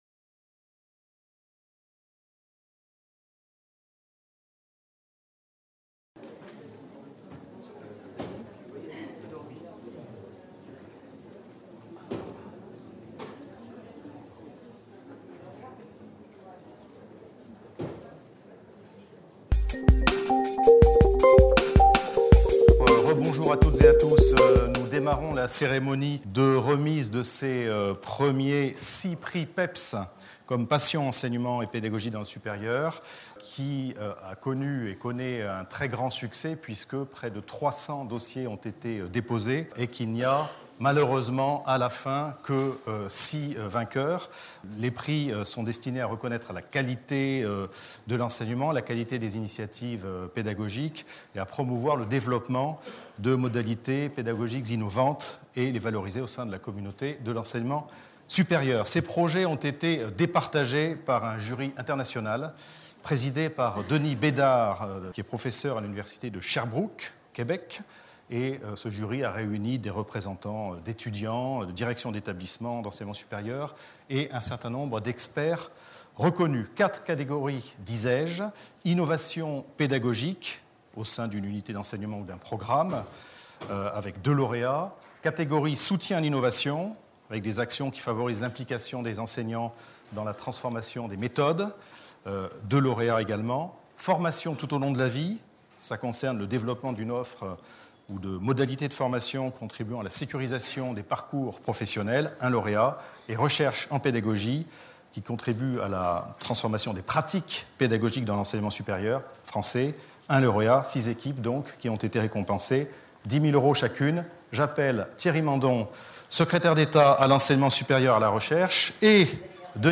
Jeudi 31 mars 2016 Remise du prix PEPS 2016 (Passion Enseignement et Pédagogie dans le Supérieur) par Thierry Mandon, secrétaire d’État chargé de l’Enseignement supérieur et de la Recherche Les 6 initiatives récom